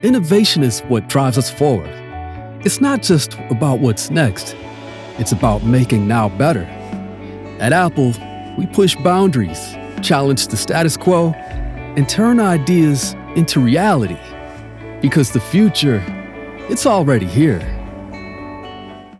Apple - Real, Inspiring, Cutting Edge, Innovative, intuitive
North American, African American, Southern, Eastcoast
I record projects using the Focusrite Scarlet Solo, CM25 MkIII condenser mic and REAPER DAW.